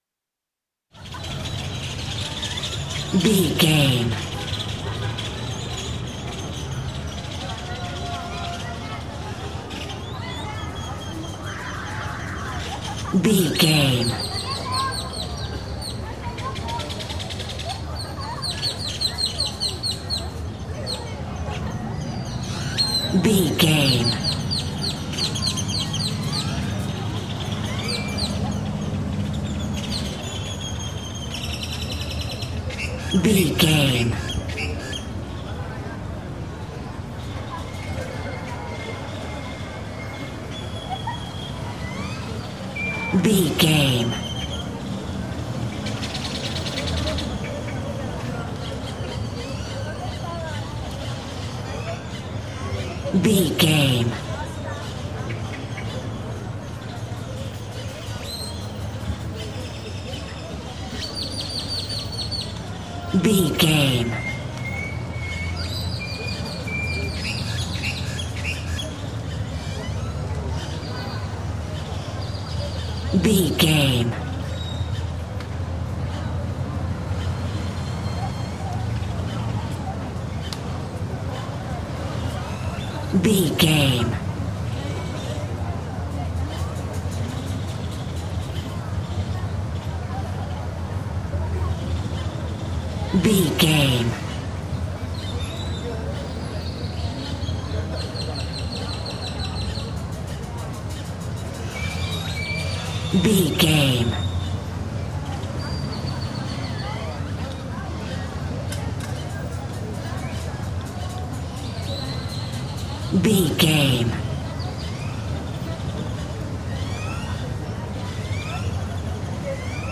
Mexico taxco street downtown
Sound Effects
urban
chaotic
ambience